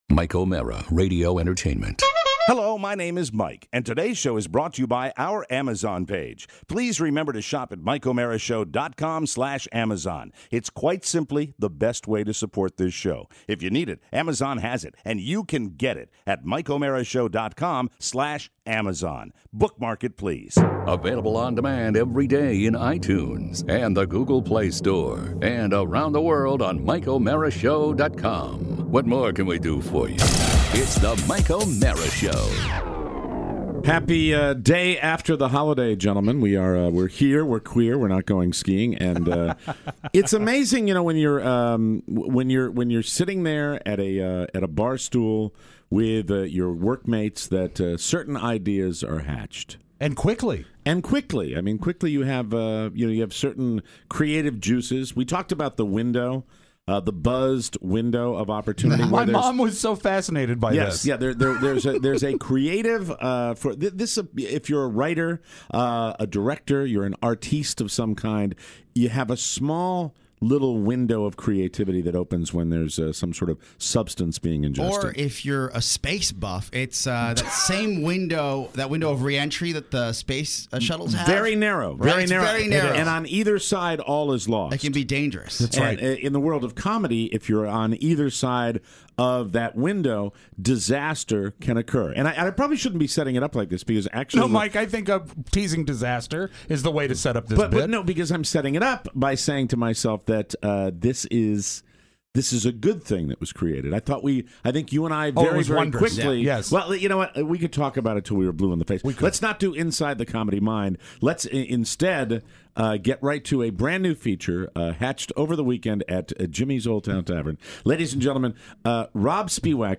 In studio!